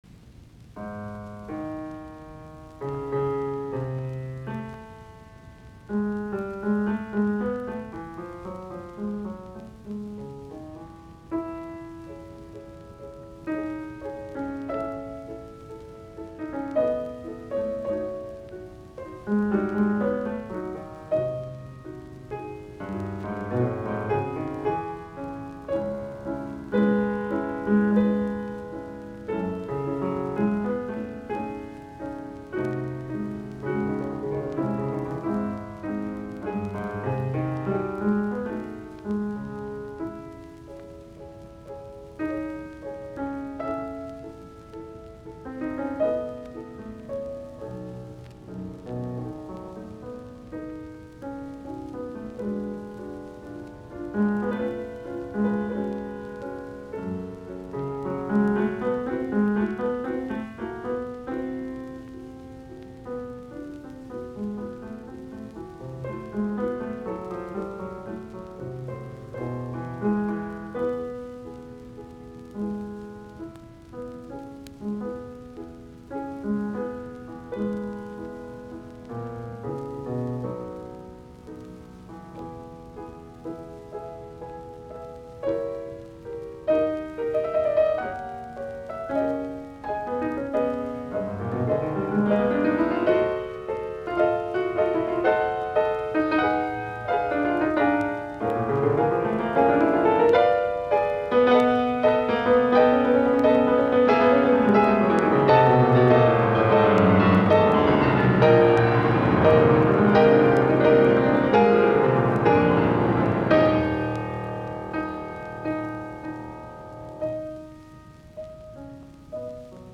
Soitinnus: Piano.